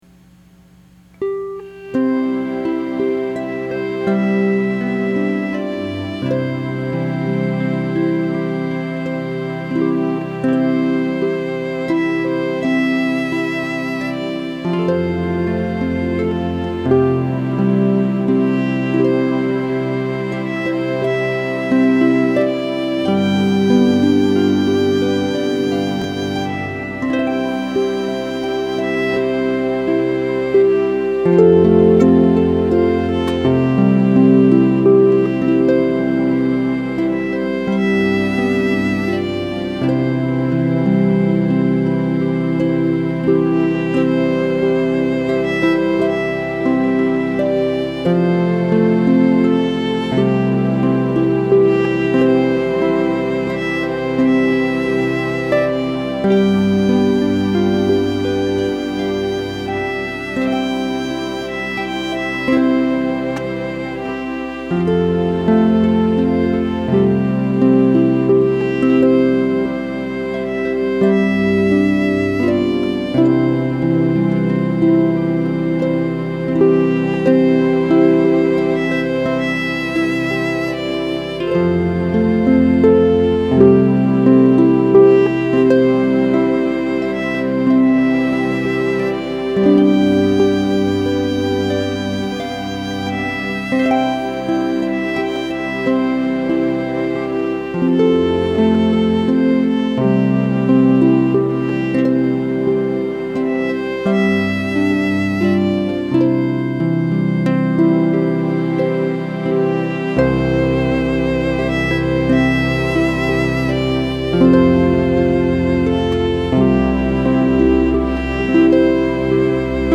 hymns played on the Harp with background music to include
violin, flute and orchestra.